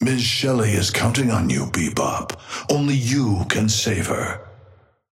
Patron_male_ally_bebop_start_01.mp3